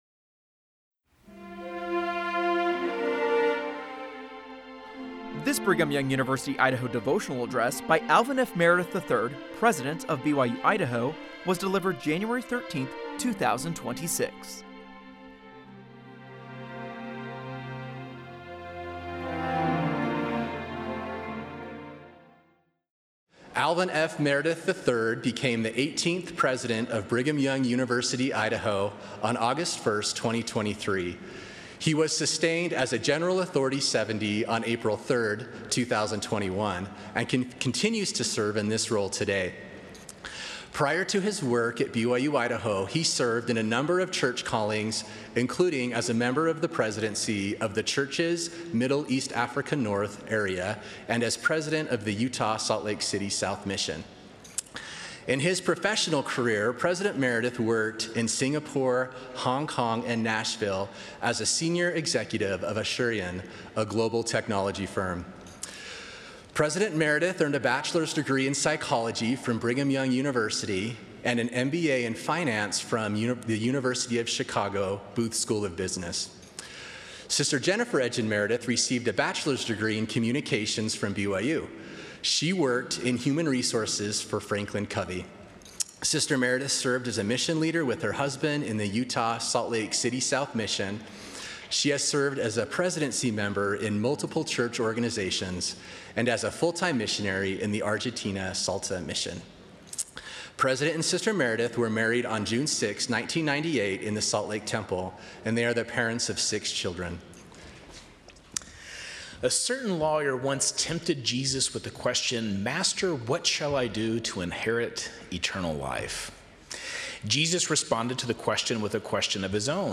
This Devotional address
was delivered on Tuesday, January 13, 2026, at 11:30 AM MST in the BYU-Idaho I-Center.